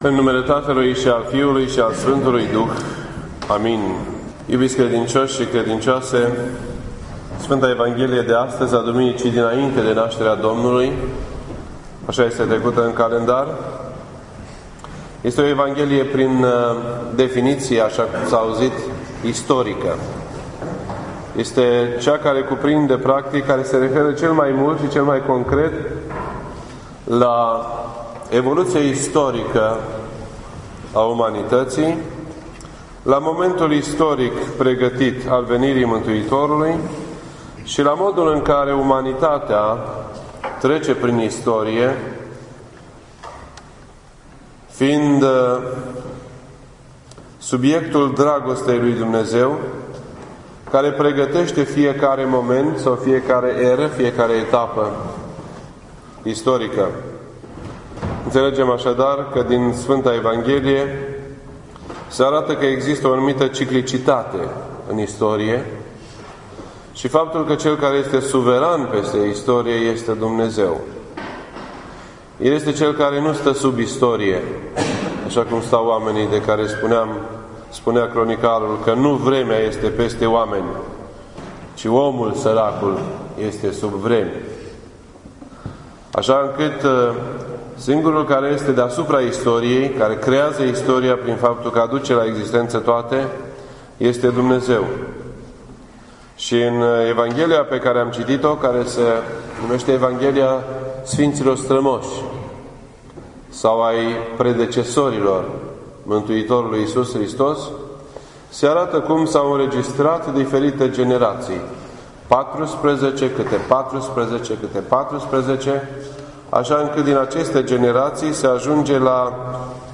This entry was posted on Sunday, December 23rd, 2012 at 7:48 PM and is filed under Predici ortodoxe in format audio.